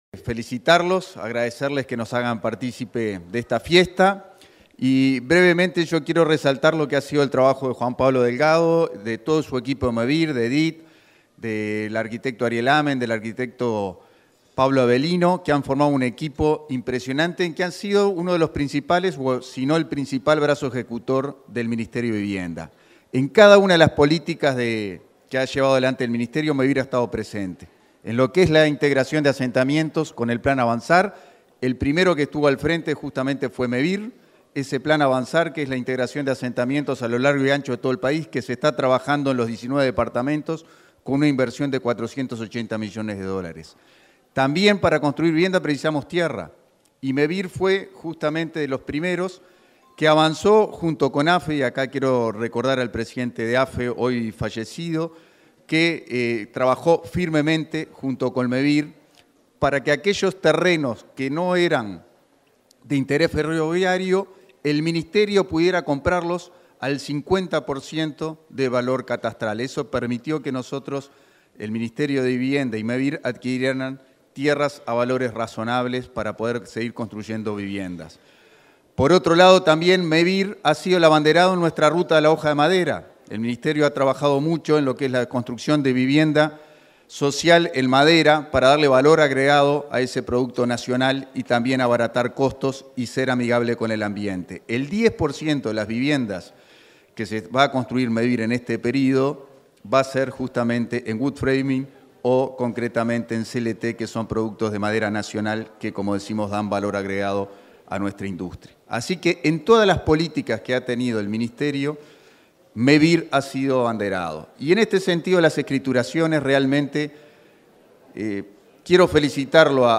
Palabras del subsecretario de Vivienda, Tabaré Hackenbruch
Durante el acto de escritura de 120 viviendas en Sarandí Grande, este 10 de diciembre, se expresó el subsecretario de Vivienda, Tabaré Hackenbruch.